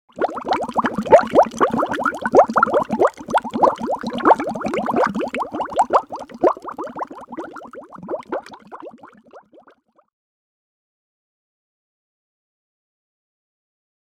BUBBLES BURBUJILLAS
Ambient sound effects
Bubbles_burbujillas.mp3